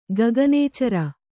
♪ gaganēcara